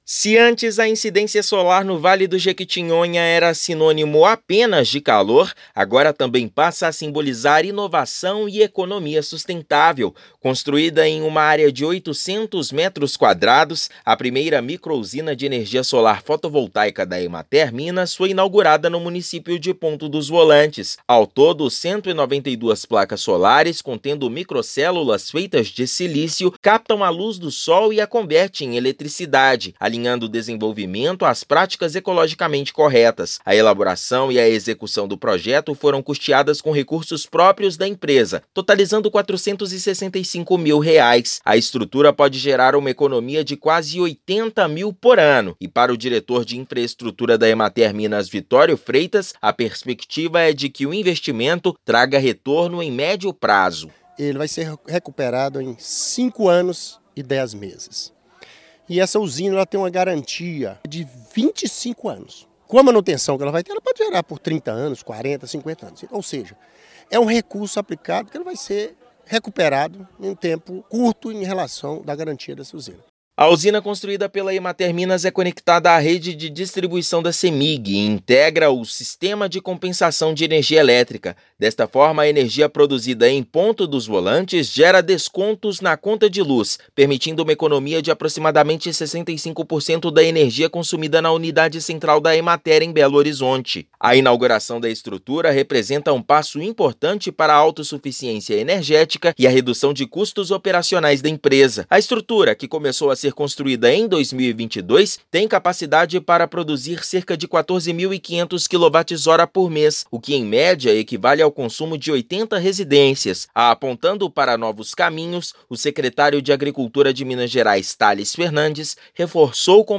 A usina compensa o consumo de energia elétrica da sede da empresa em Belo Horizonte. Ouça matéria de rádio.